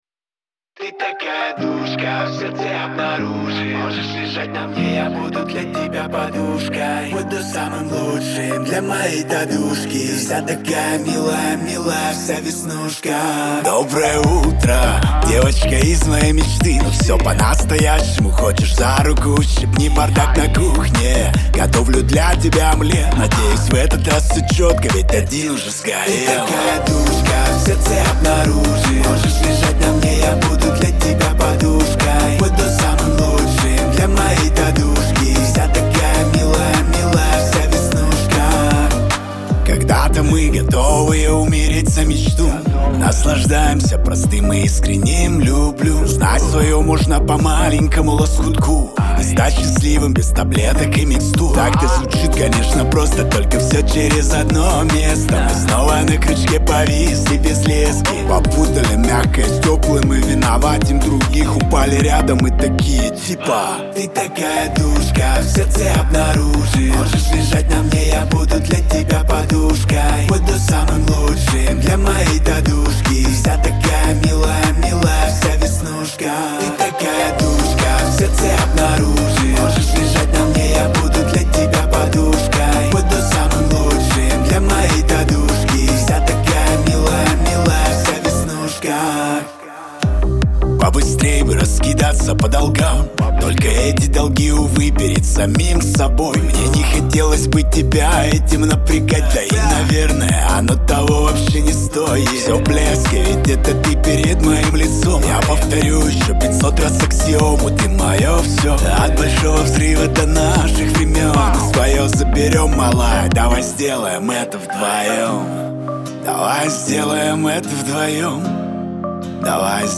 ХАУС-РЭП
эстрада